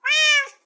meow4.ogg